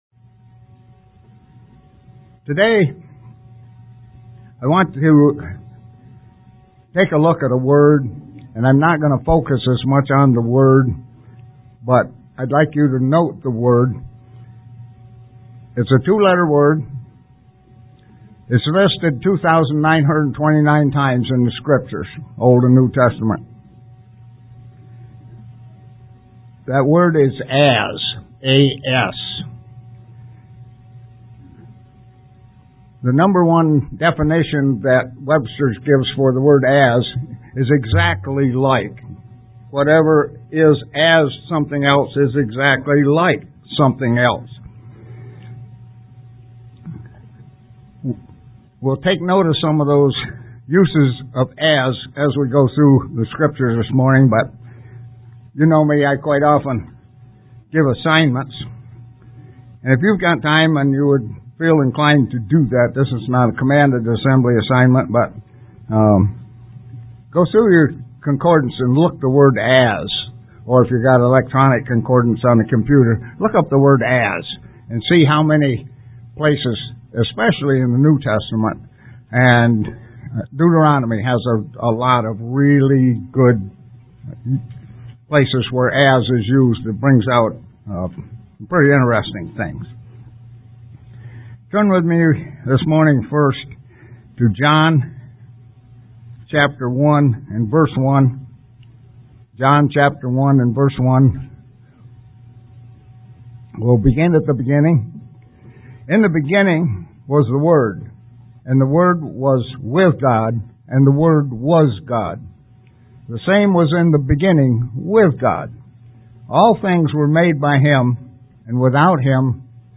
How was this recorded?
Given in Buffalo, NY